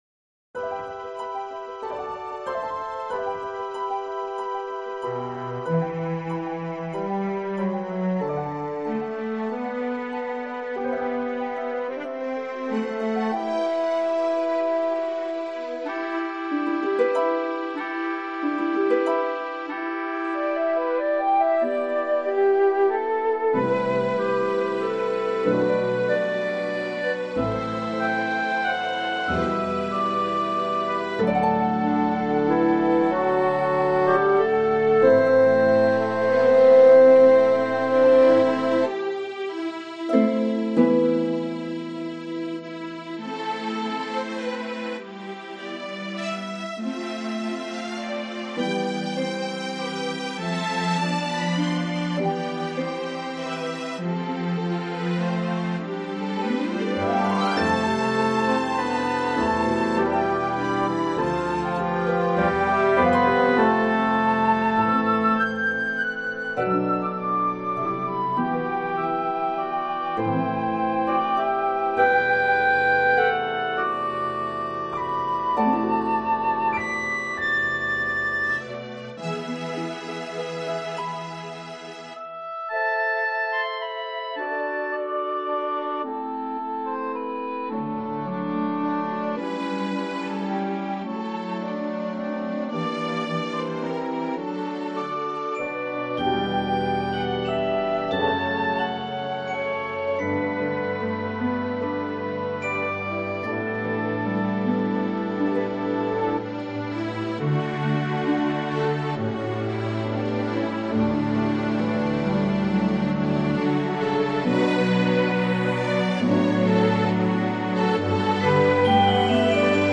A full-orchestra tone poem